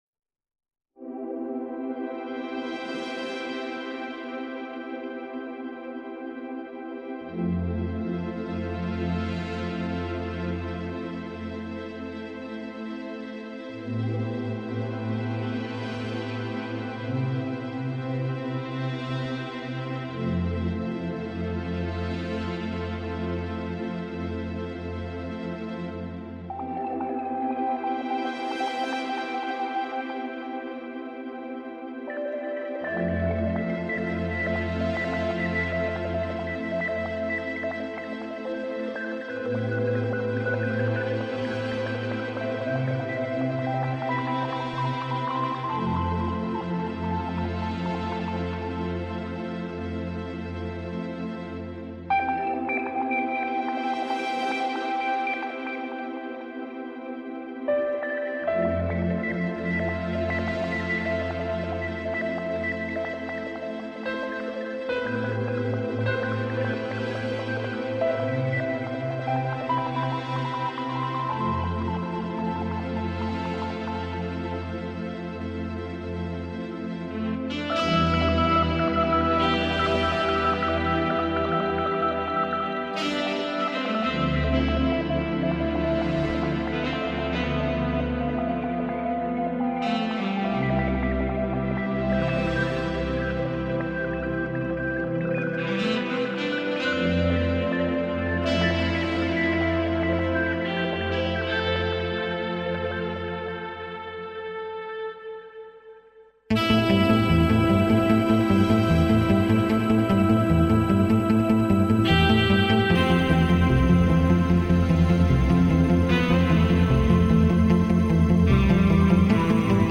[2007-4-5]情緒音樂放鬆心情 Blue(藍色)